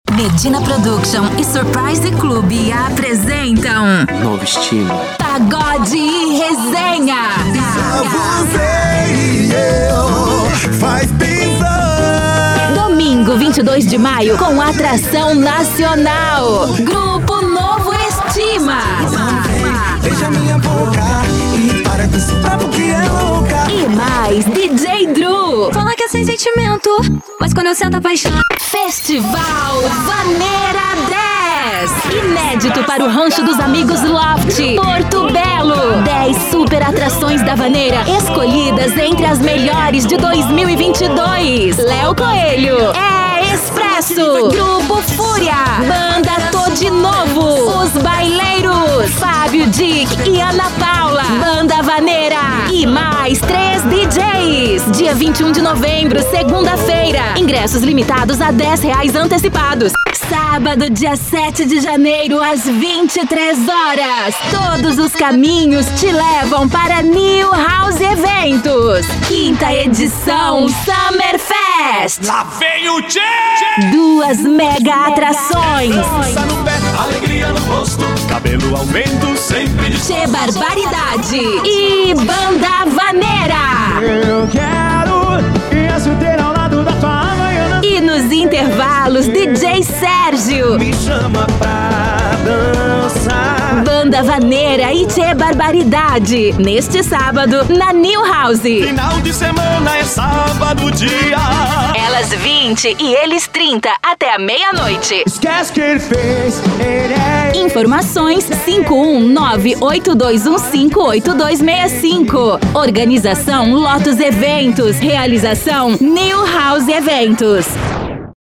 Feminino
Portfólio Chamada Festa, Up, Pra cima
Voz Jovem 01:58
Tenho voz jovem, natural, facilidade para interpretação, agilidade na entrega do trabalho e bons equipamentos.